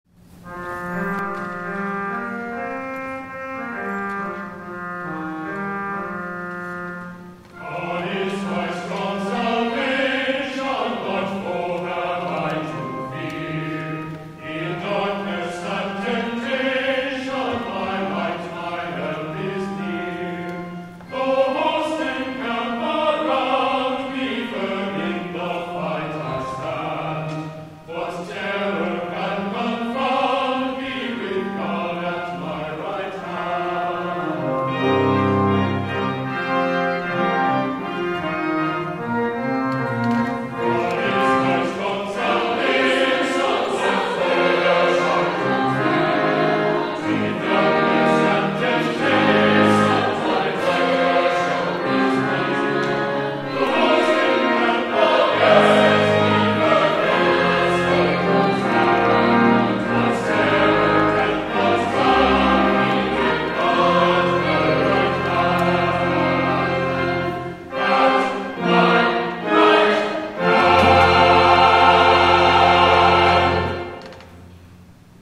* THE CHORAL RESPONSE
(based on a Southern folk hymn from "The Sacred Harp"-1844)